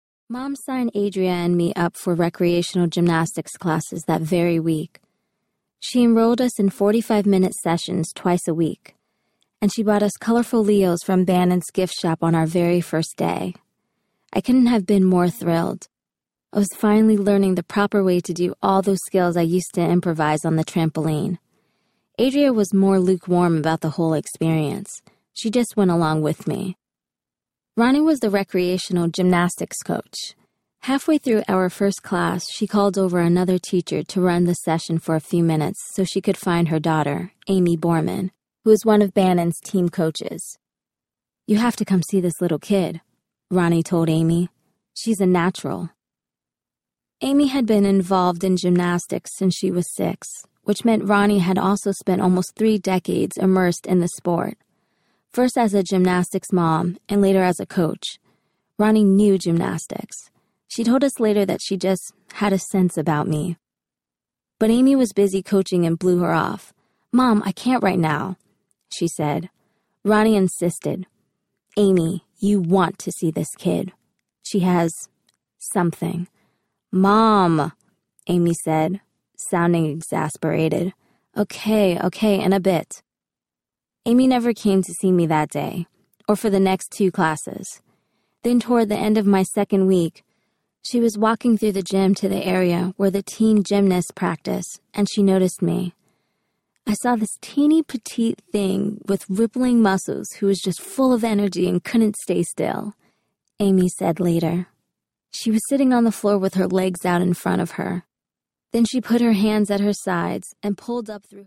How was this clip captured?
6.4 Hrs. – Unabridged